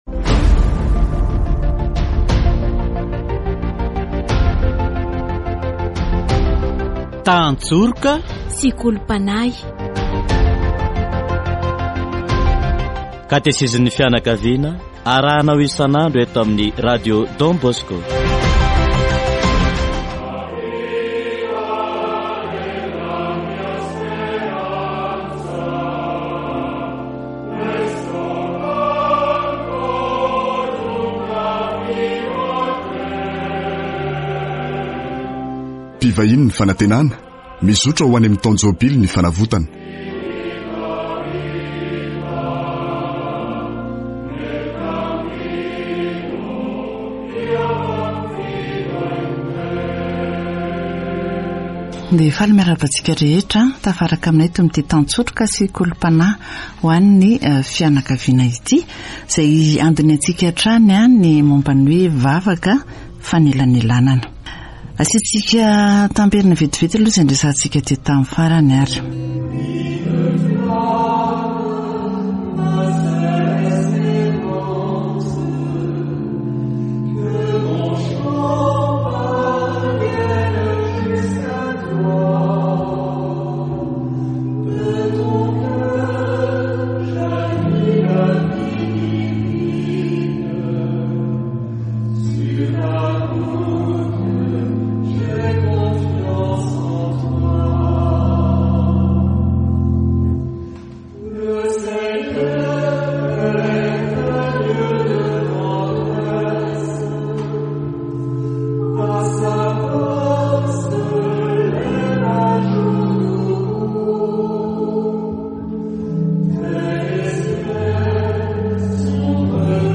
Dans le temps de l'Eglise, prière d'intercession chrétienne participe à celle du christ : elle est l'expression de la communion des saints. Catéchèse sur La prière d'intercession